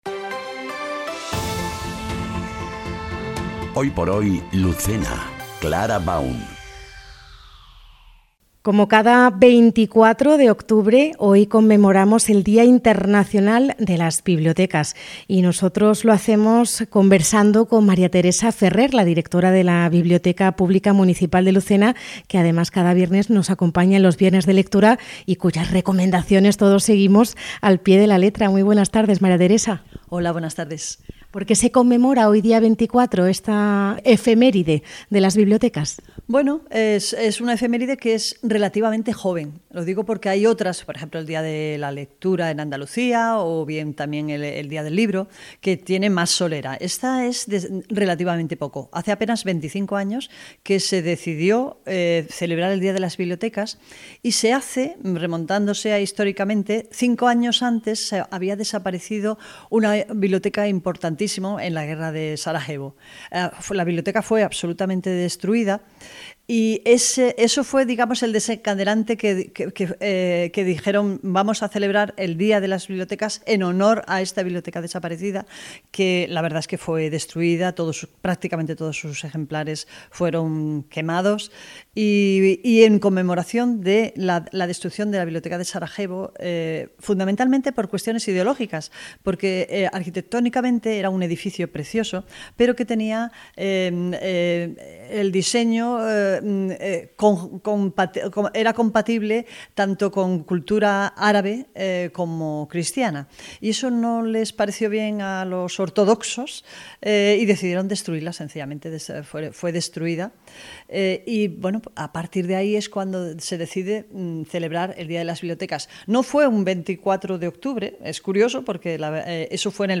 ENTREVISTA |Día Internacional de las Bibliotecas en Lucena